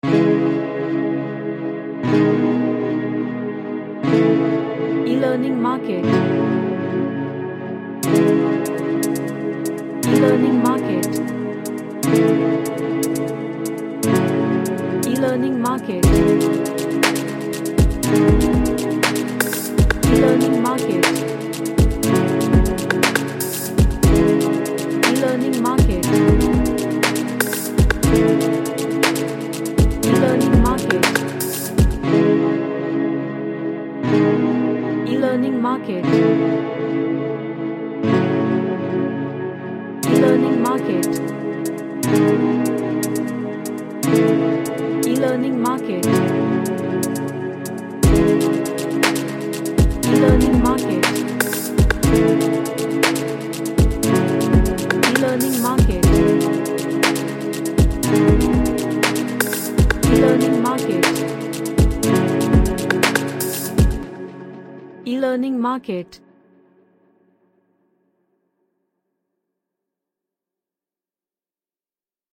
A nice progressive track with chill vibe to it.
Emotional